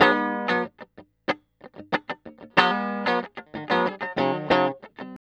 TELEDUAL C 3.wav